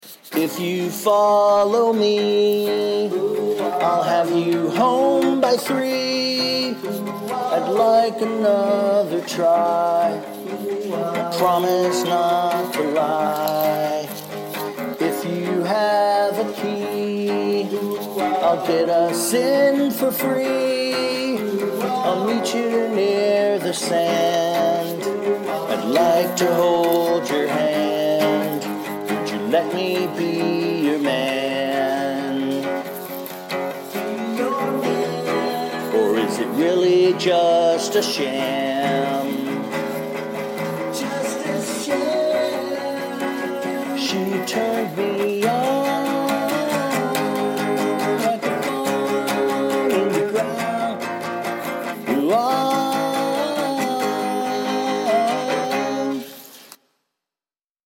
Although goddamnit it kind of sounds like I am saying "If you have a pee" when I am actually saying "If you have a key".